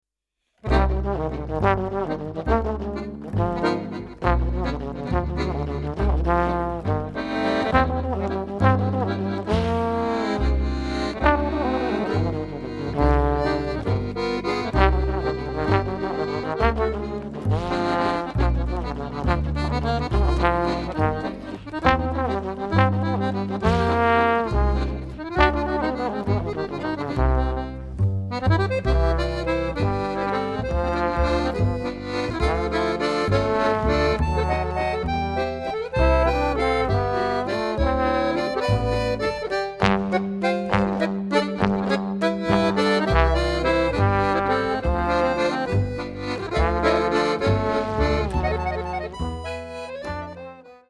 Accordéon / Ténor Lyrique
Trombone
Contrebasse
au Studio Les Tontons Flingueurs (Renaison - France)